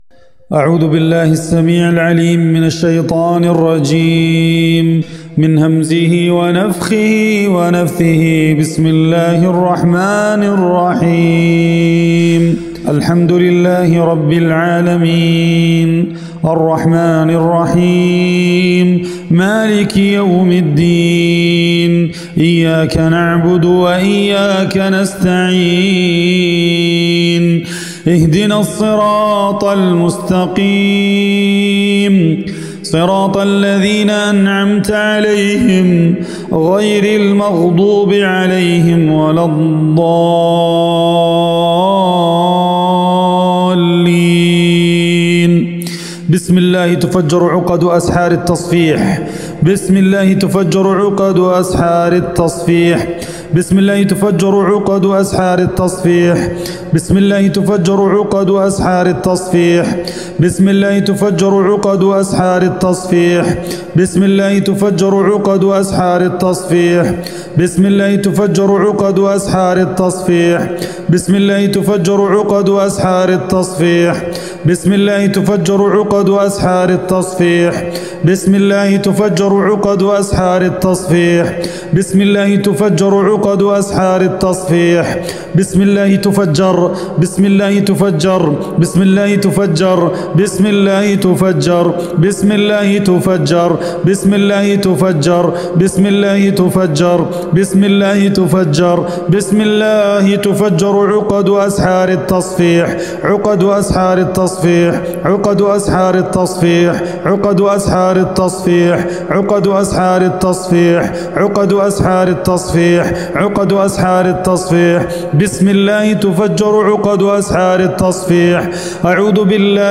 রুকইয়াহ অডিও